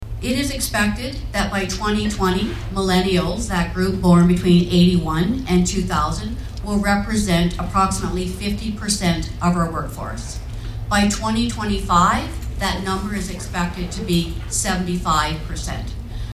At Belleville’s Ramada Hotel, they welcomed the Municipality of Brighton to the event, which focused on thanking the business community, and talked about the changing workplace.